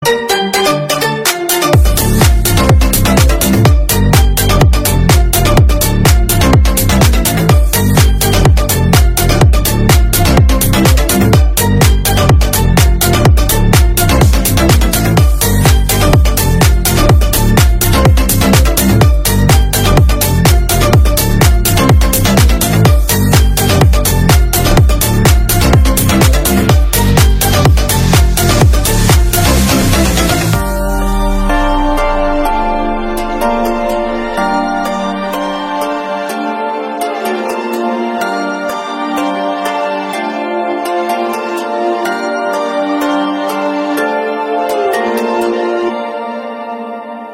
Kategorien Elektronische